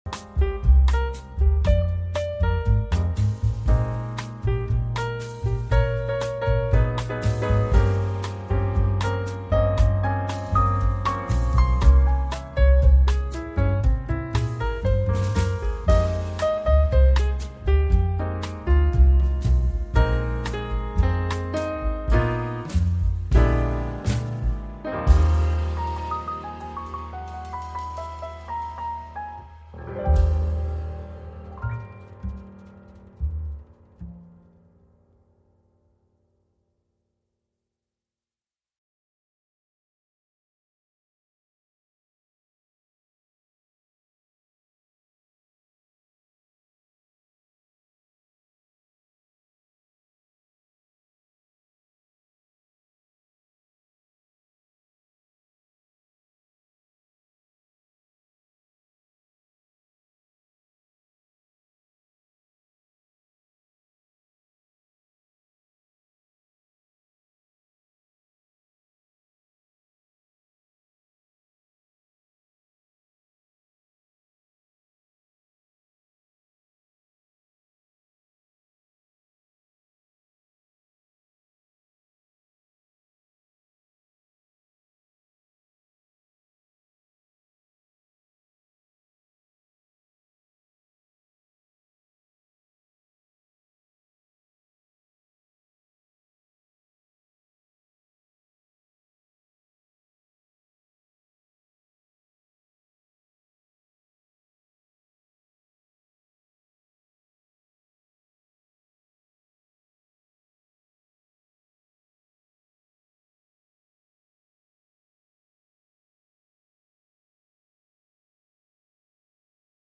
Apaisement Anxiété : Fréquences Douces